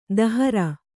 ♪ dahara